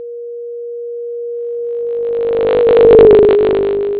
Velocity of object: 25m/s
Sound generated by source: 440Hz tone
The SoundClip1 shifts pitch correctly, but there are artifacts as the pitch changes.